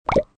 合成螺丝.MP3